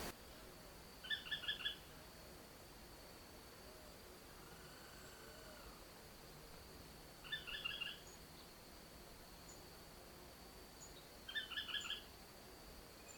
Barranqueiro-de-olho-branco (Automolus leucophthalmus)
Nome em Inglês: White-eyed Foliage-gleaner
Fase da vida: Adulto
Localidade ou área protegida: Reserva Privada y Ecolodge Surucuá
Condição: Selvagem
Certeza: Observado, Gravado Vocal